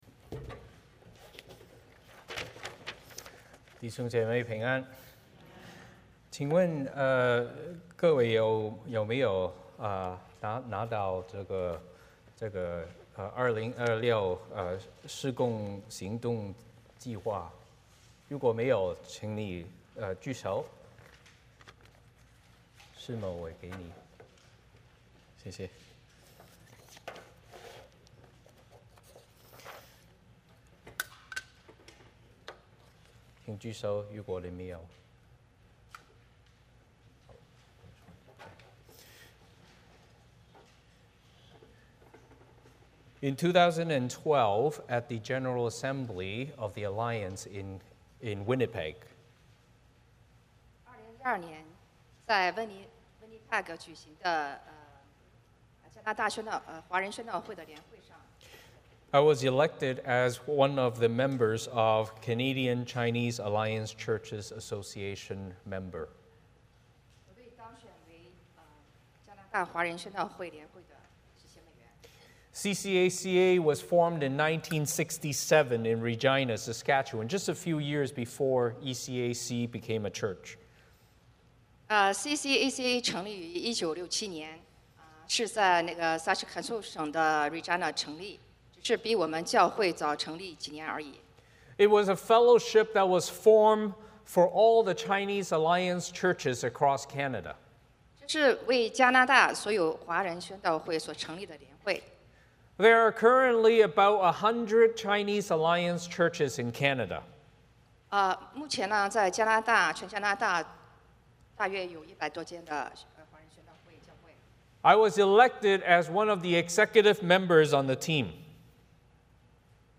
欢迎大家加入我们国语主日崇拜。
10 Service Type: 主日崇拜 欢迎大家加入我们国语主日崇拜。